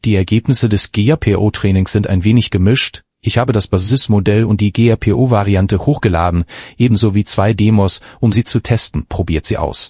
Here a sample with a random speaker:
The GRPO variant performs similar or slightly worse on the random speaker.